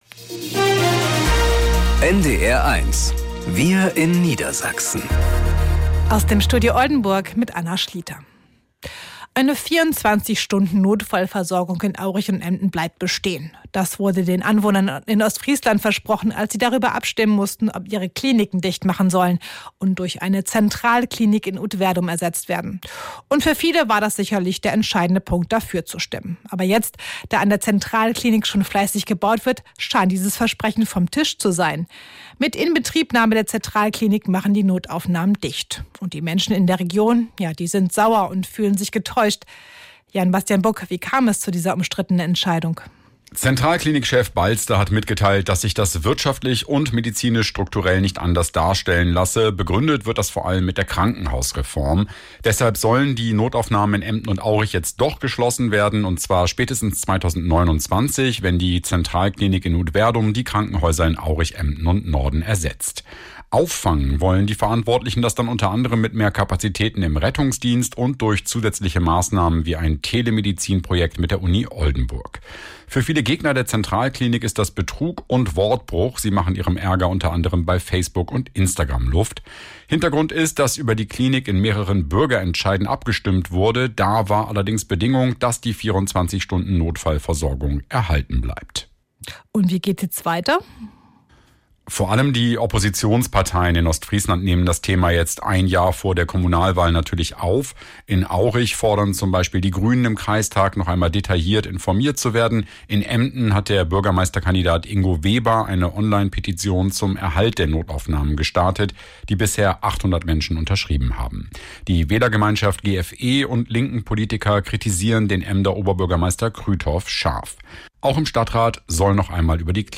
… continue reading 5 つのエピソード # Tägliche Nachrichten # Nachrichten # NDR 1 Niedersachsen